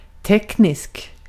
Ääntäminen
IPA : /ˈtɛk.nɪk.əl/